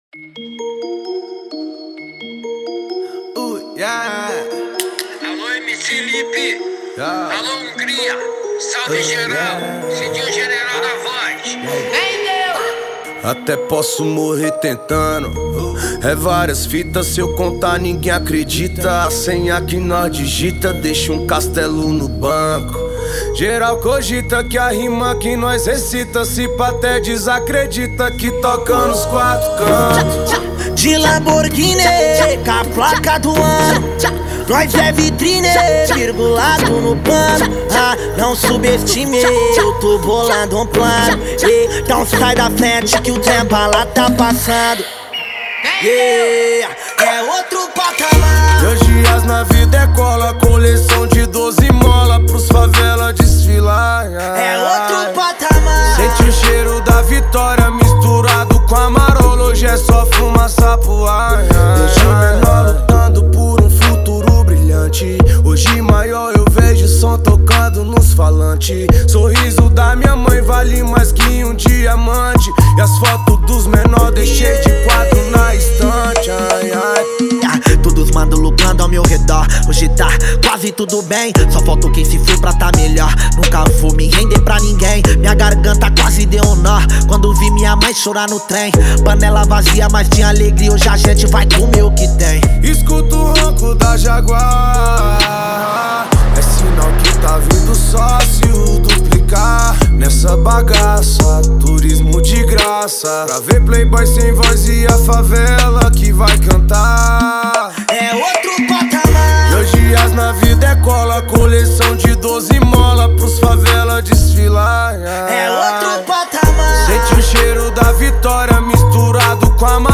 2025-02-23 15:56:51 Gênero: Rap Views